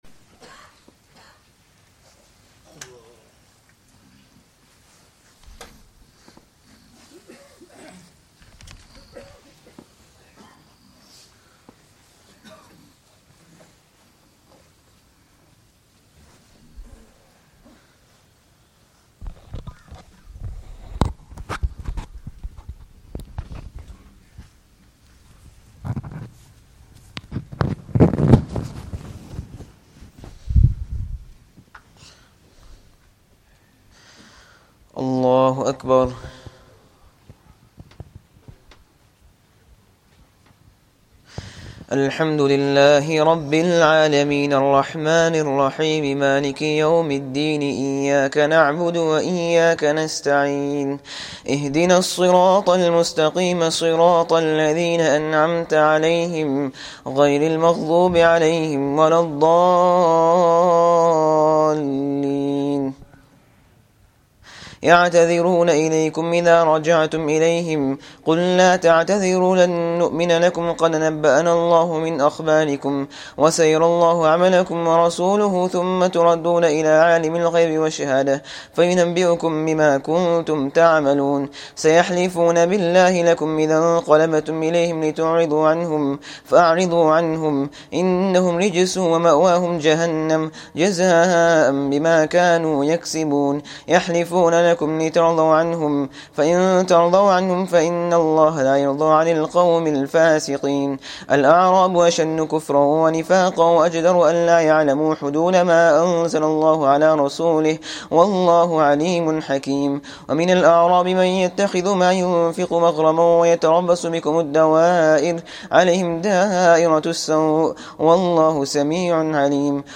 9th Taraweeh 2021